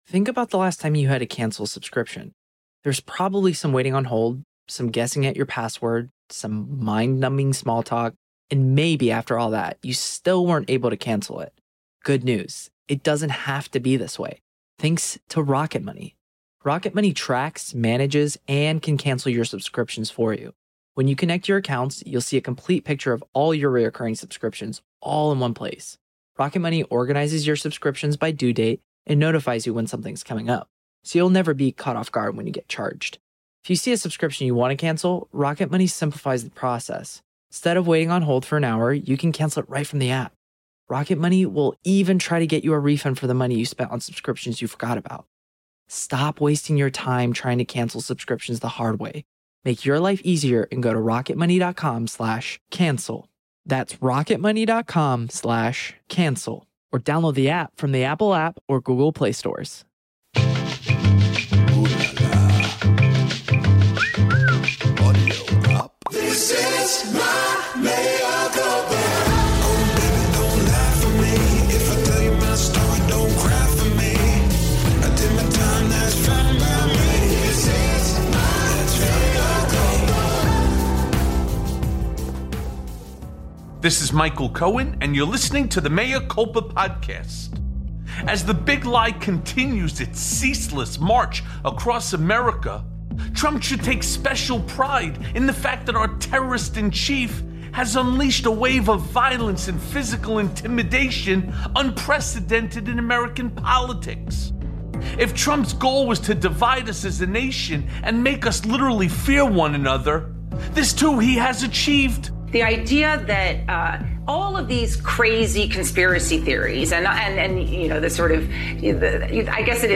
Later, Richard Painter joins Mea Culpa to discuss the former presidents legal liability and how he and his cronies will go to prison.